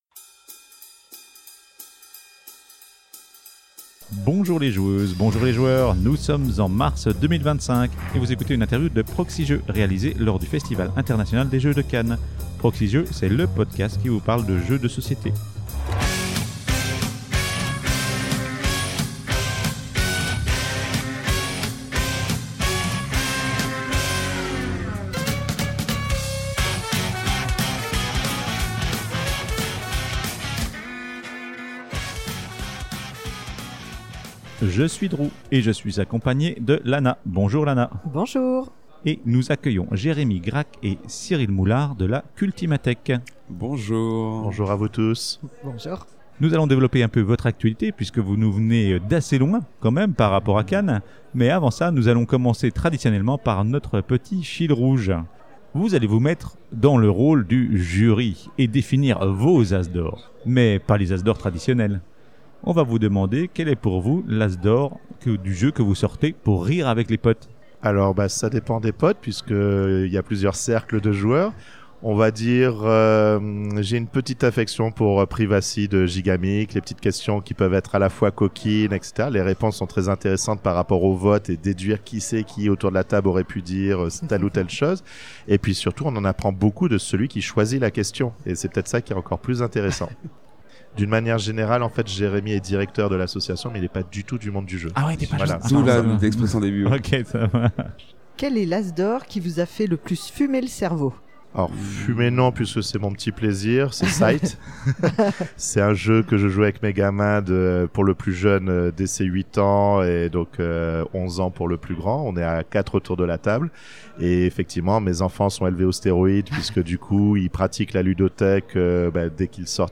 Lors de son séjour au Festival International des Jeux de Cannes, la Proxi-Team a rencontré de nombreuses actrices et de nombreux acteurs du monde du jeu de société.